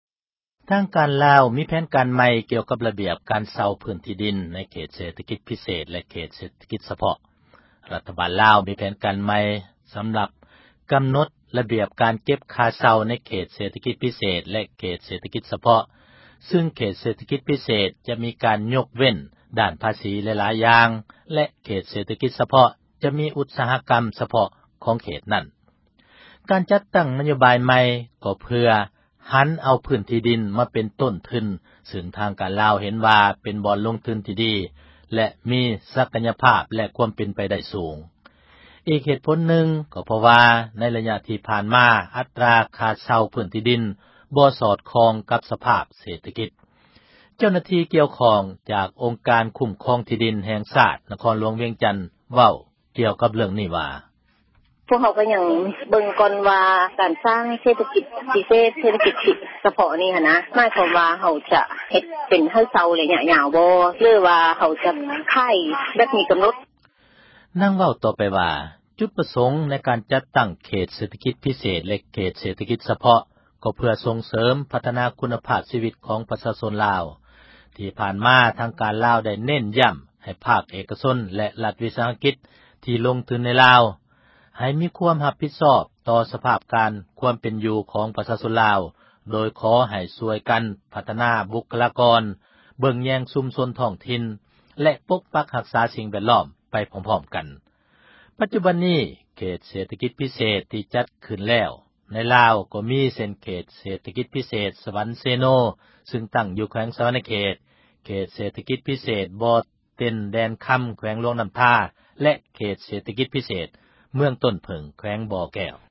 ອີກເຫດຜົນນຶ່ງ ກໍເພາະວ່າ ໃນລະຍະທີ່ຜ່ານມາ ອັດຕຣາຄ່າເຊົ່າ ພື້ນທີ່ດິນ ບໍ່ສອດຄ່ອງກັບ ສະພາບເສຖກິດ. ເຈົ້າໜ້າທີ່ກ່ຽວຂ້ອງ ຈາກອົງການຄຸ້ມຄອງ ທີ່ດິນແຫ່ງຊາດ ນະຄອນຫລວງ ວຽງຈັນ ເວົ້າກ່ຽວກັບເຣື້ອງນີ້ວ່າ: